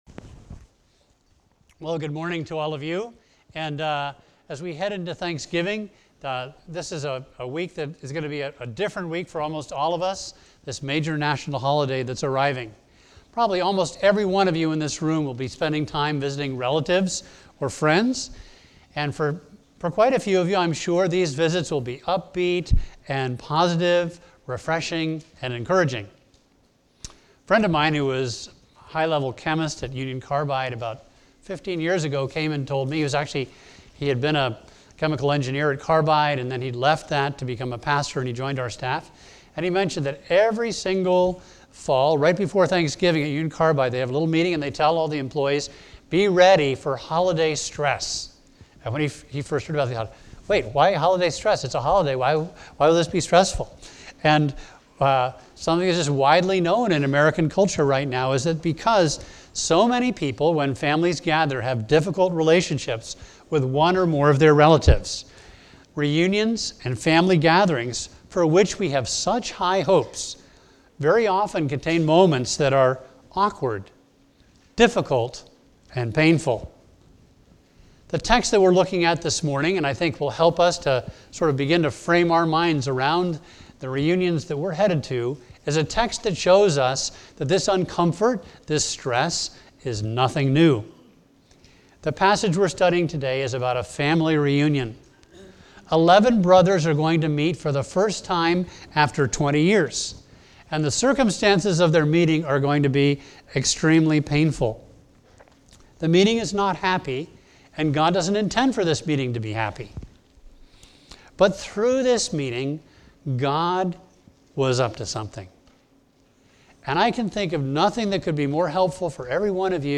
A message from the series "Heaven Help The Home."